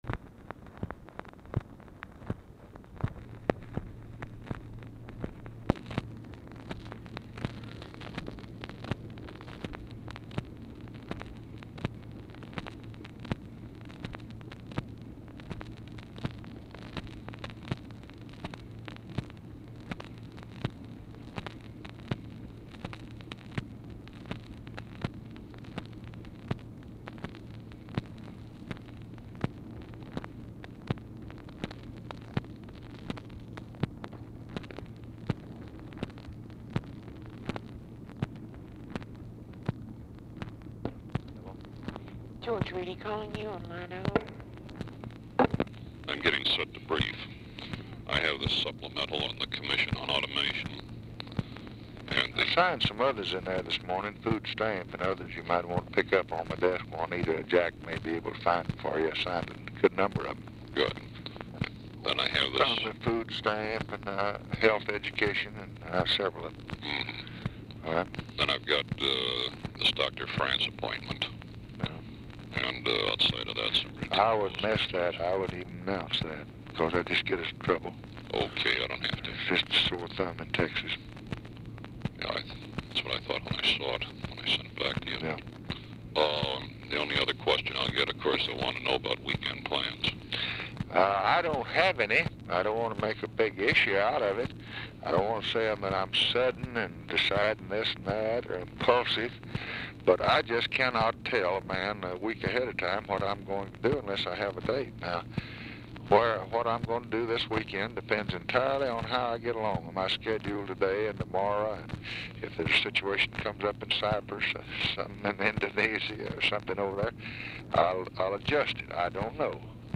REEDY ON HOLD 0:40; STATIC CAUSED BY GREASE PENCIL ON ORIGINAL DICTABELT
Format Dictation belt
Specific Item Type Telephone conversation